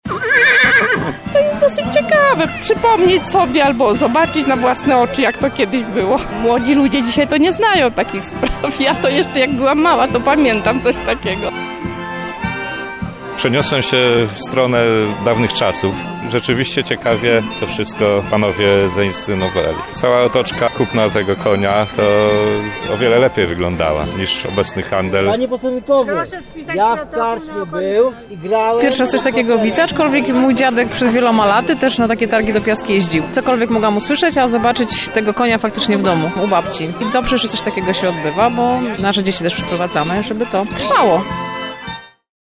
Podczas Jarmarku Końskiego przygrywała Kapela Piecha.